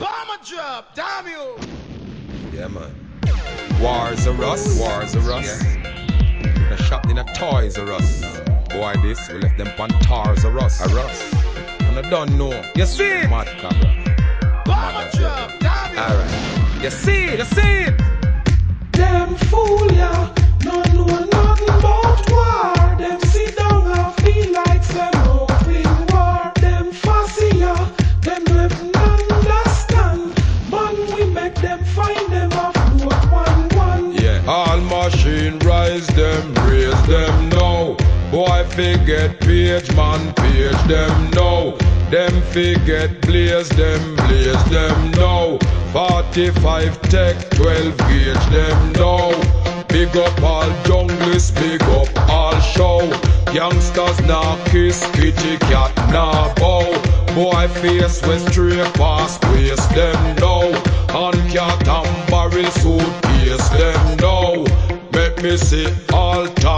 REGGAE
大ヒット超高速ダンスホール・トラック!!